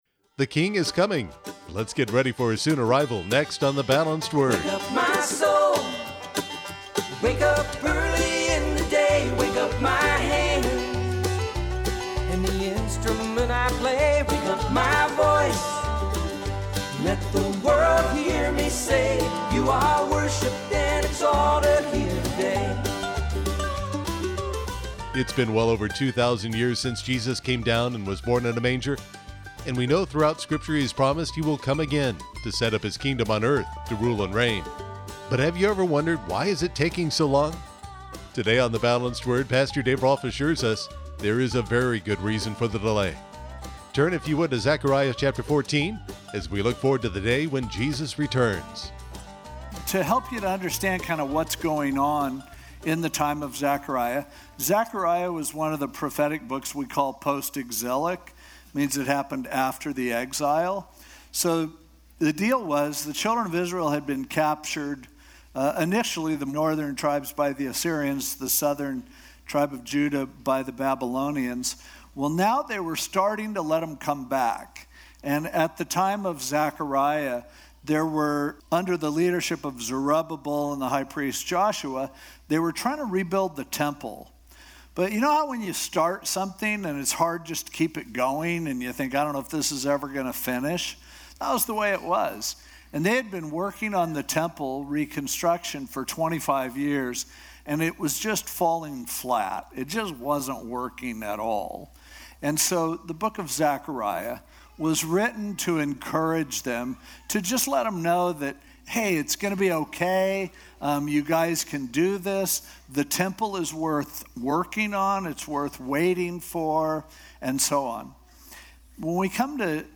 This podcast features the podcasted radio messages